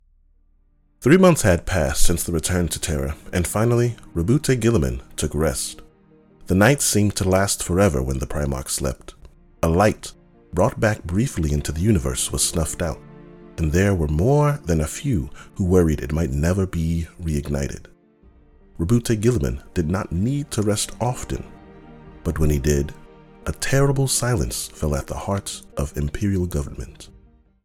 Book Reading
US Southern, US General
Young Adult
Relaxed Narration.mp3